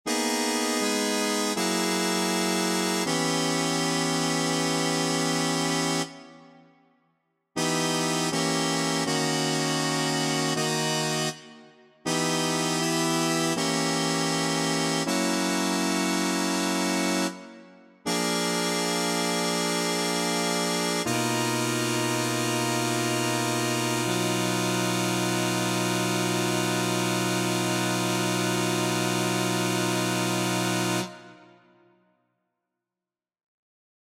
Key written in: B Major
How many parts: 4
Type: Barbershop
All Parts mix: